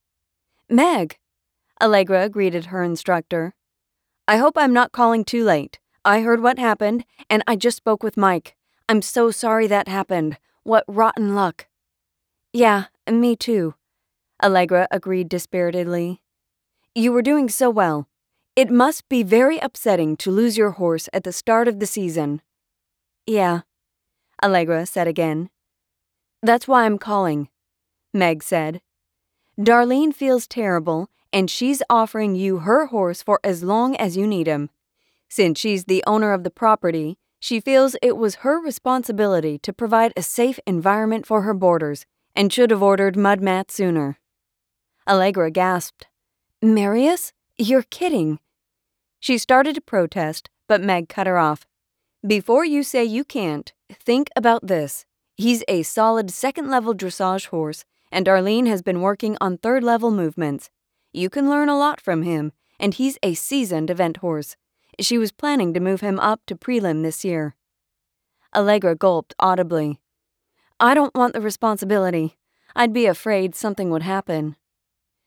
Female, North American neutral accent, with an adult/young-adult vocal sound. Pitch is not too high and not too low, with a smooth vocal quality. Can be upbeat, happy and conversational or polished and professional depending on the read and desired tone.
Audiobooks
Contemporary Romance Fiction